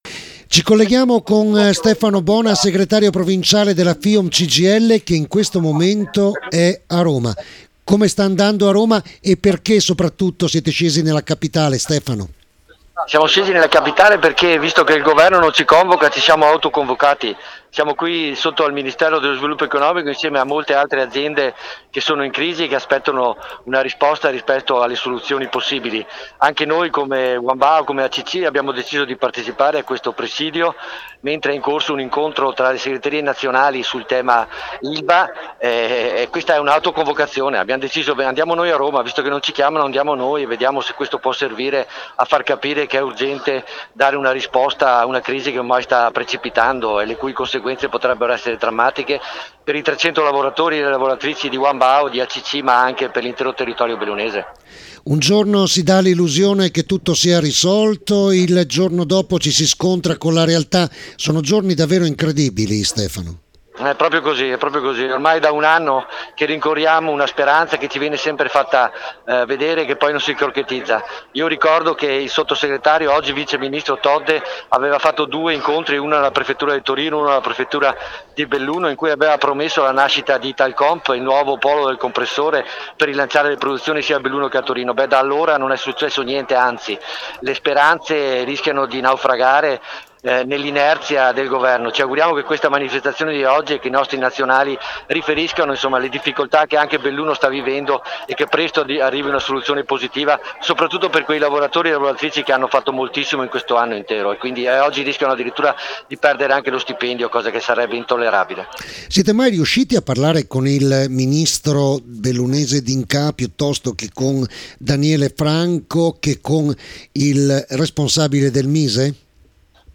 REDAZIONE Manifestazione a Roma, diretta RADIO PIU’ 1030